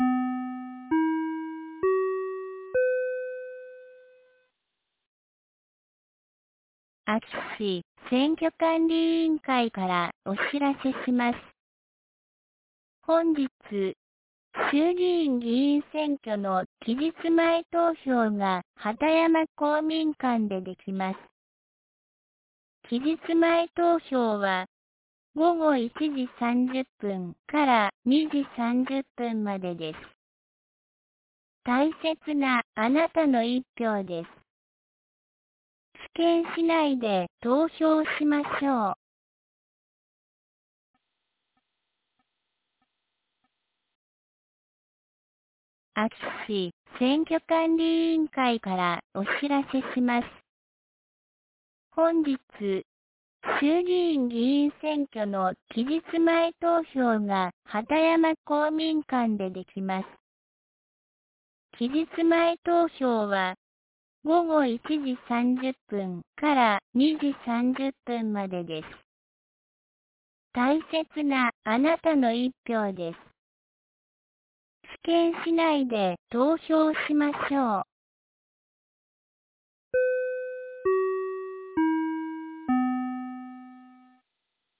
2024年10月24日 09時05分に、安芸市より畑山へ放送がありました。